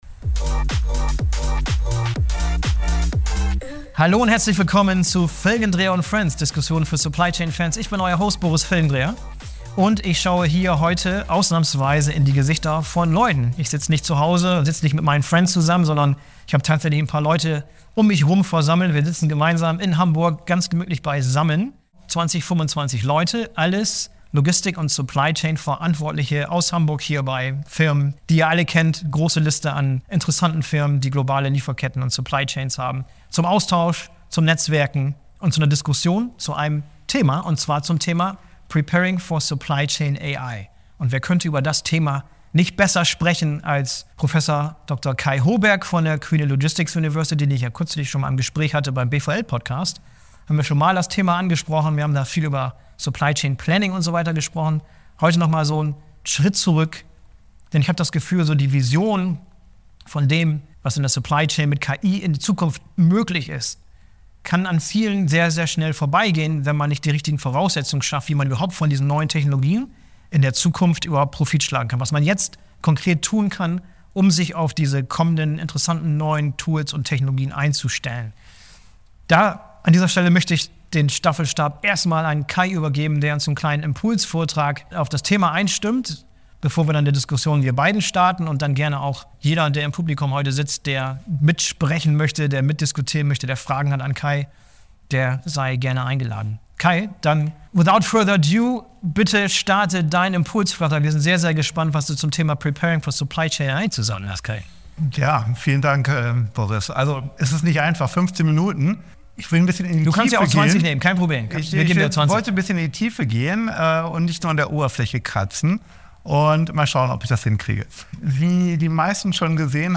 All das und mehr haben wir in einer Gesprächsrunde mit einem Live-Publikum von Supply Chain und Logistik Verantwortlichen aus Industrie und Handel in Hamburg diskutiert.